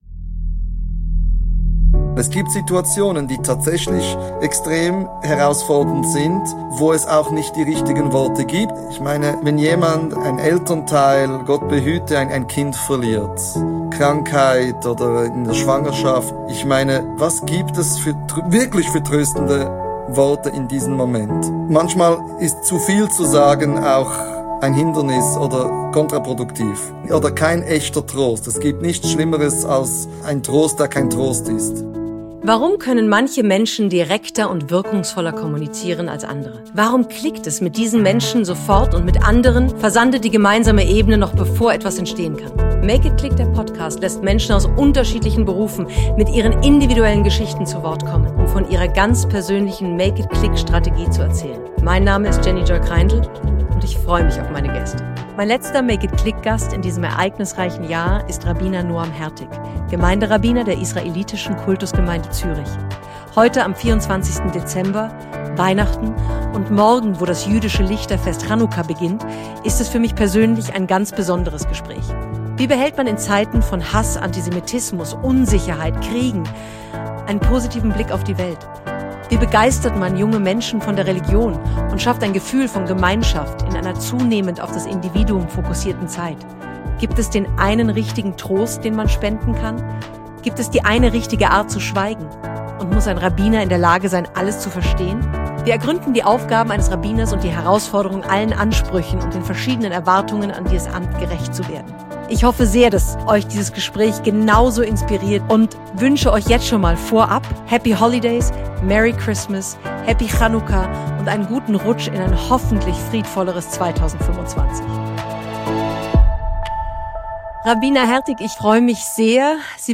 Es ist für mich persönlich ein ganz besonderes Gespräch, welches ich stundenlang hätte führen können und in dem wir buchstäblich über «Gott und die Welt» sprechen. Wir ergründen die Aufgaben eines Rabbiners und die Herausforderung, allen Ansprüchen und den verschiedenen Erwartungen an dieses Amt gerecht zu werden. Wie behält man in Zeiten von Hass und Unsicherheit einen positiven Blick auf die Welt?